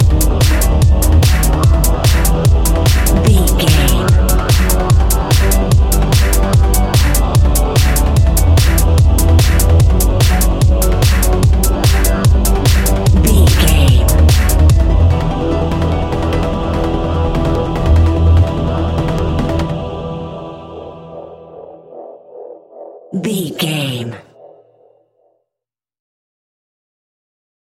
royalty free music
Aeolian/Minor
Fast
futuristic
hypnotic
epic
dark
drum machine
synthesiser
electronic
uptempo
synth leads
synth bass